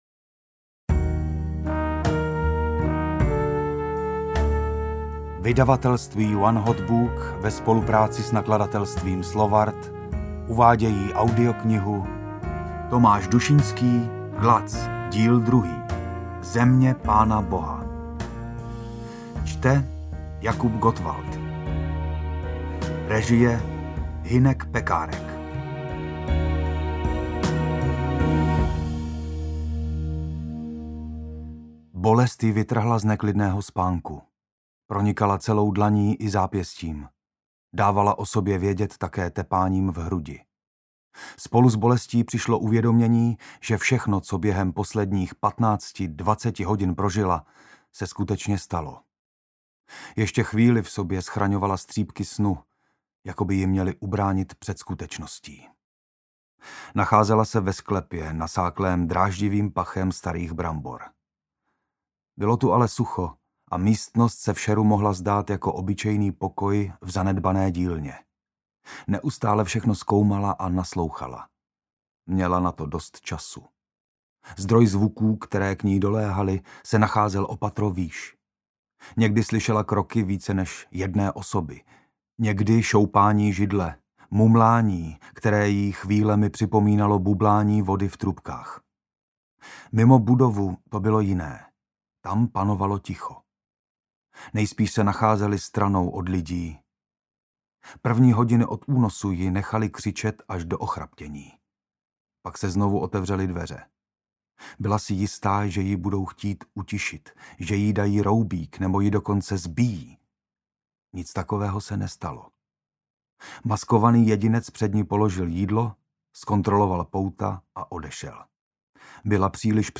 Glatz - Země Pána Boha audiokniha
Ukázka z knihy
• InterpretJakub Gottwald